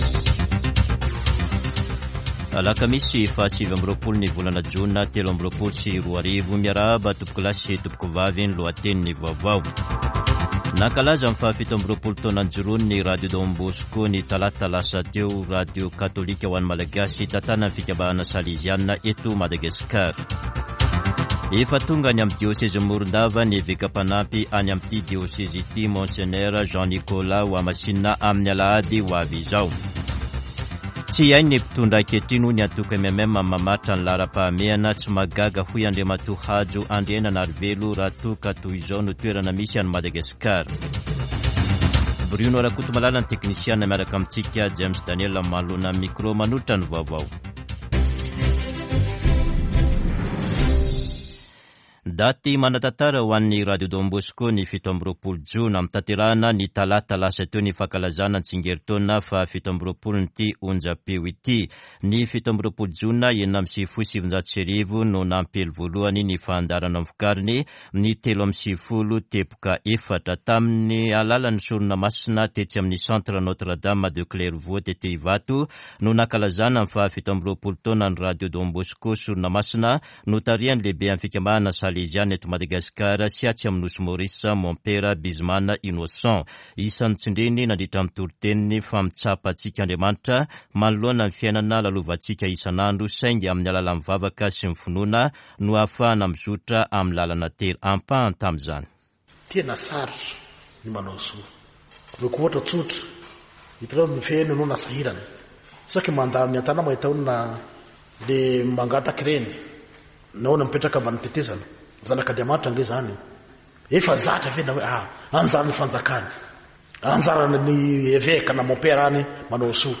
[Vaovao maraina] Alakamisy 29 jona 2023